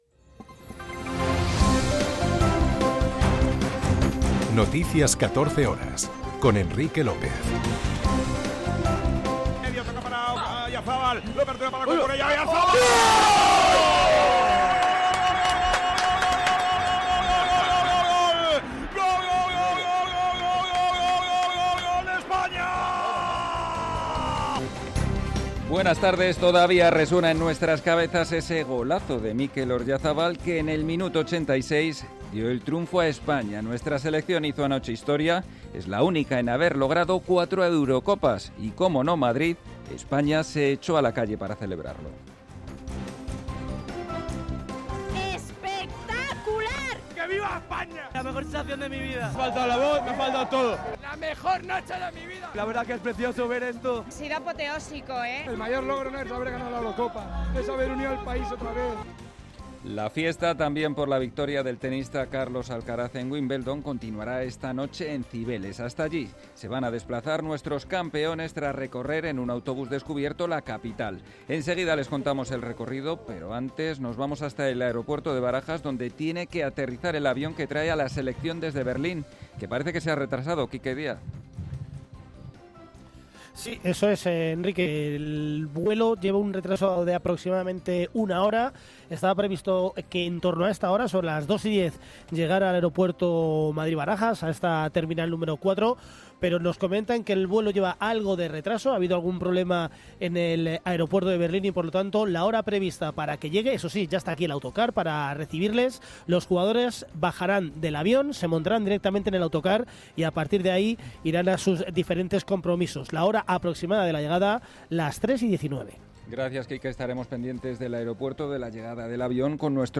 en España y en el mundo. 60 minutos de información diaria con los protagonistas del día y conexiones en directo en los puntos que a esa hora son noticia.